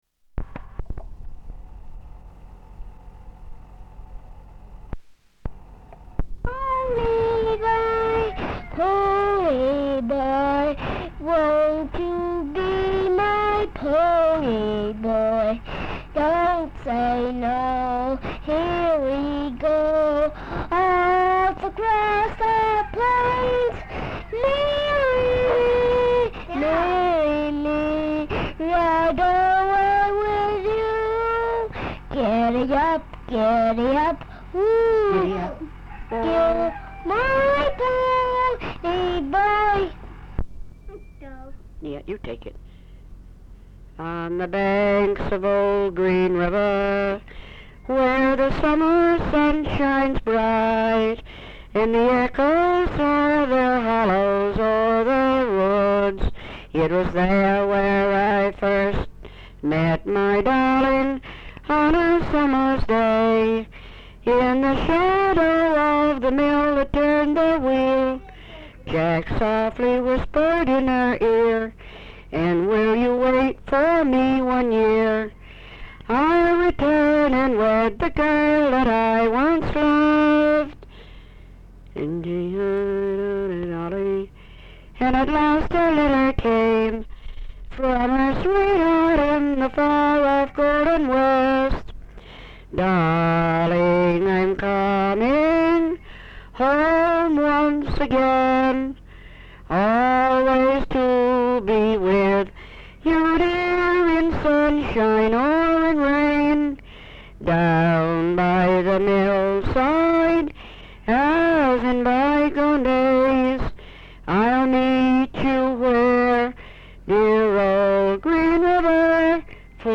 Folk songs, English--Vermont
1 sound tape reel (Polyester) : analog ; full track, mono.